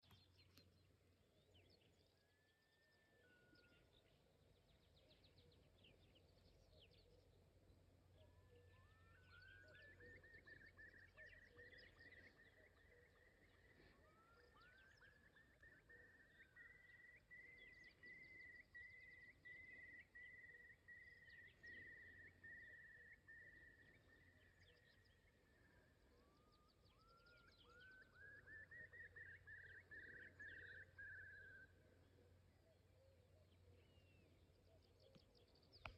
Putni -> Bridējputni -> 1
Lietuvainis, Numenius phaeopus
StatussDzied ligzdošanai piemērotā biotopā (D)